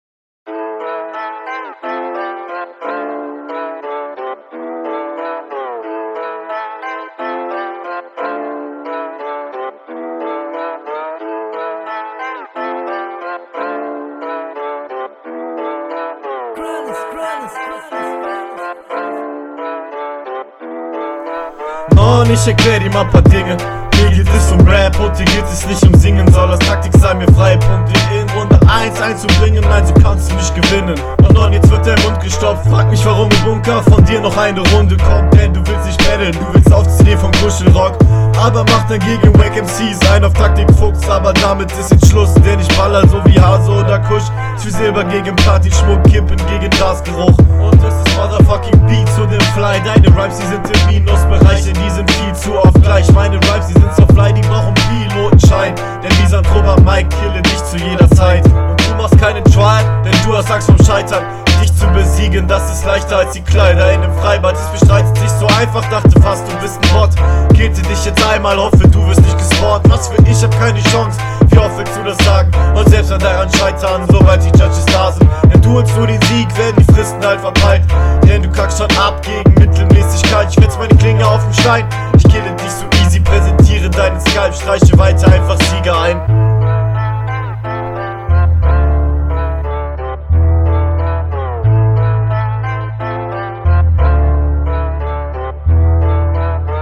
Der Beat ist ein bisschen laut, wodurch man deine Vocals nicht so gut versteht.